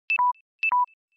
نغمة إشعار نفاذ بطارية الموبايل mp3 أشهر نغمة تنبيهات للهاتف
تحميل نغمة إشعار وتنبيه نفاذ بطارية الهاتف المحمول: Battery Low Sound mp3, تنزيل نغمة تنبيه نفاذ شحن الموبايل mp3 كنغمة إشعار لرسائل الهاتف و الواتس اب, إستمع لها أسفله وقم بتنزيلها مباشرة على هاتفك الخاص MP3, صوت تنبيه إنتهاء بطارية الموبايل.
battery_low.mp3